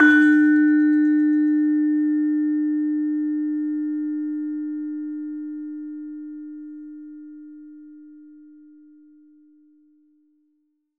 LAMEL D3  -L.wav